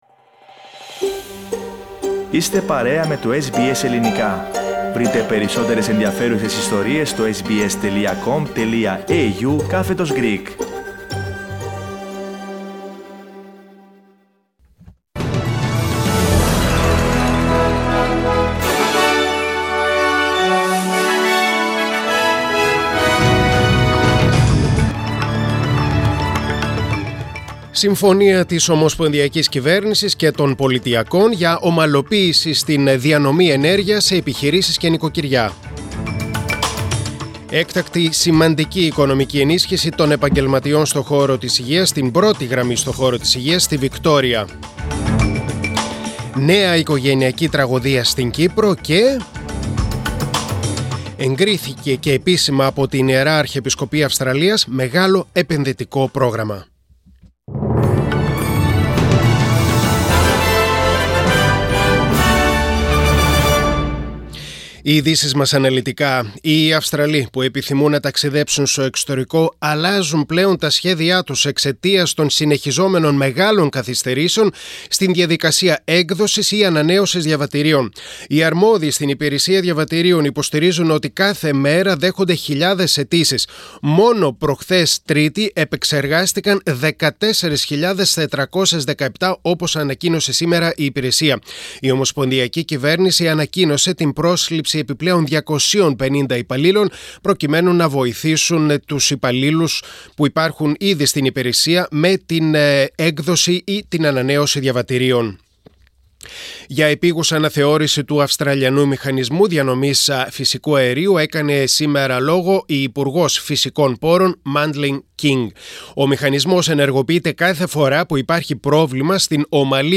Δελτίο Ειδήσεων: Πέμπτη 9.6.2022